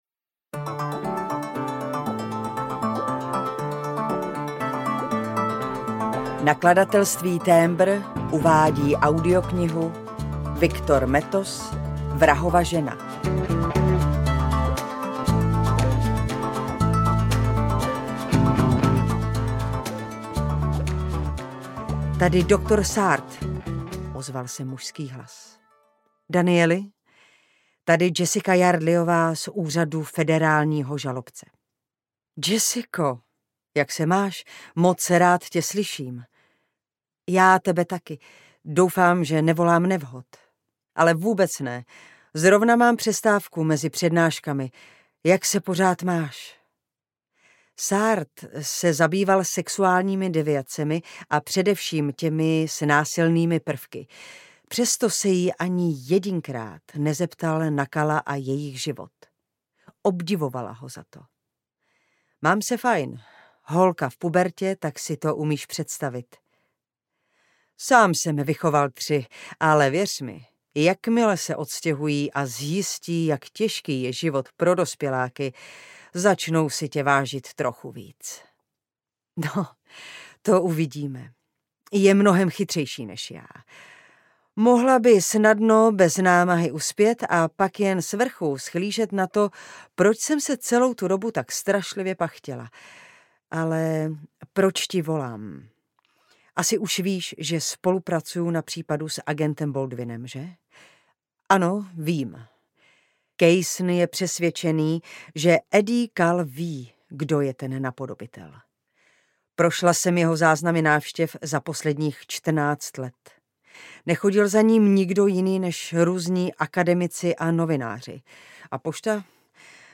Vrahova žena audiokniha
Ukázka z knihy